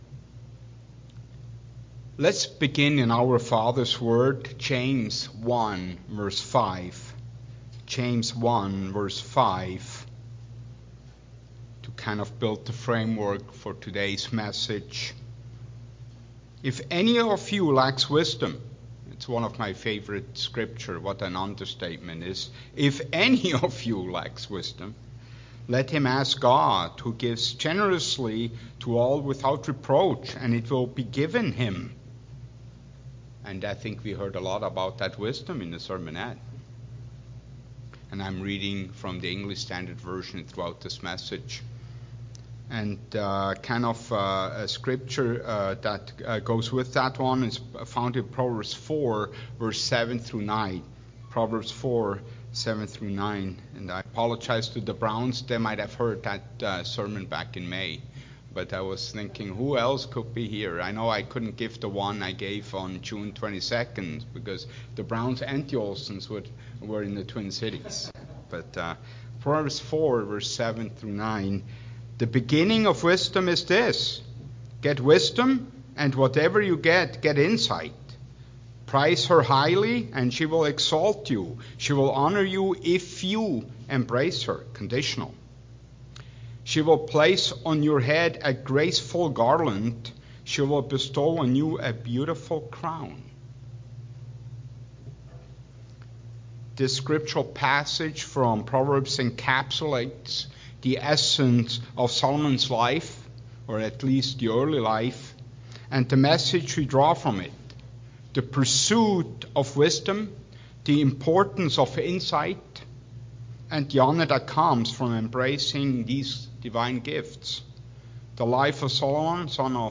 This sermon explores the life of King Solomon, emphasizing the value of wisdom and insight over material wealth.